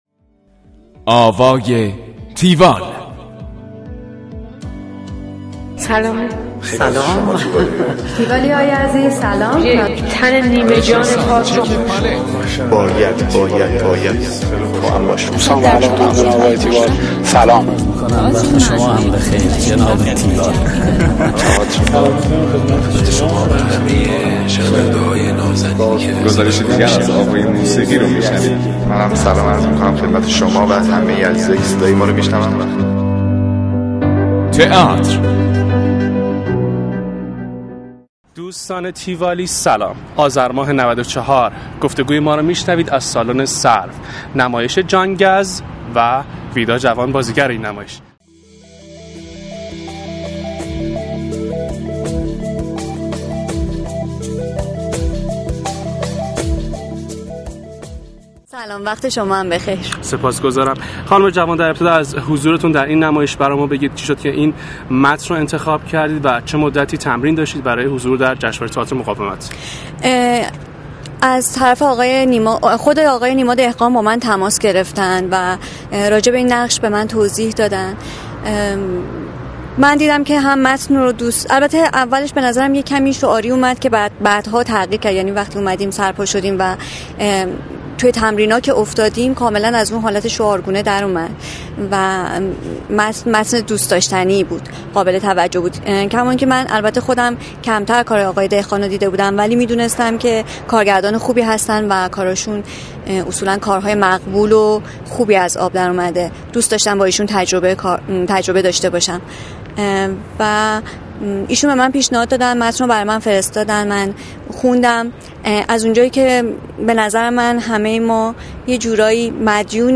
گفتگوی تیوال با ویدا جوان